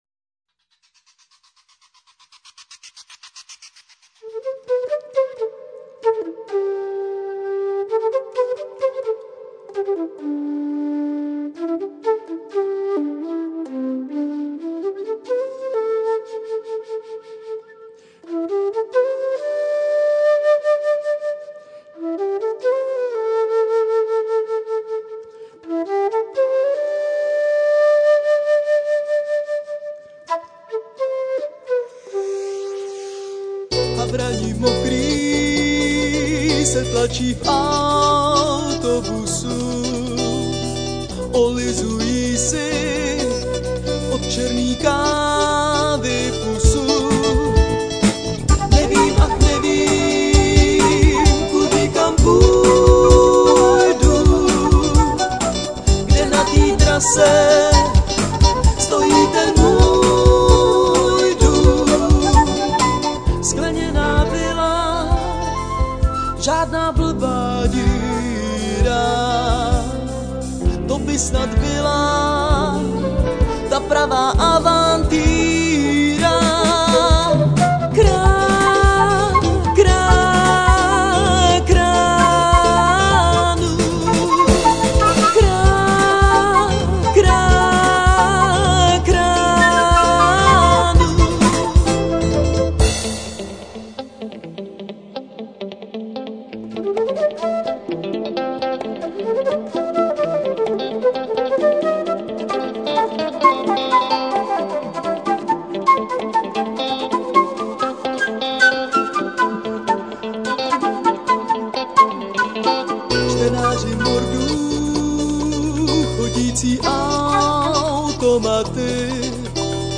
prezentuje proud elektrick², proto₧e se odehrßvß i v metru.